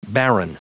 Prononciation du mot baron en anglais (fichier audio)
Prononciation du mot : baron